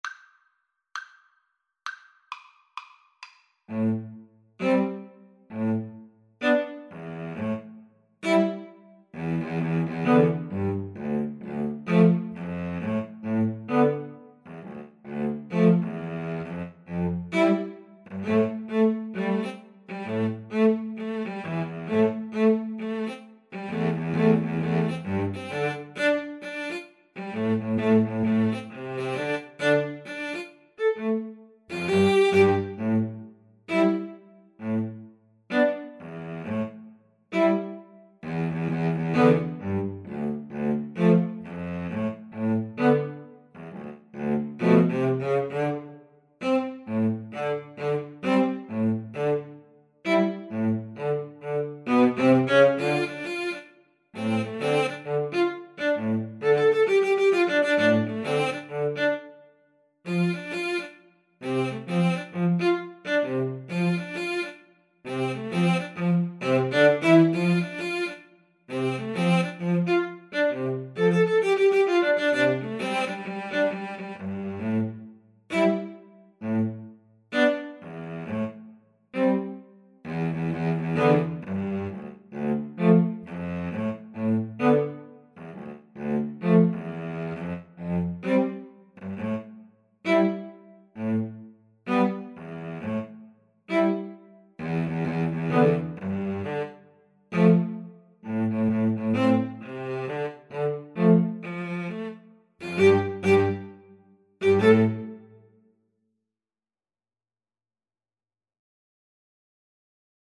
A funky hop-hop style piece.
String trio  (View more Intermediate String trio Music)
Pop (View more Pop String trio Music)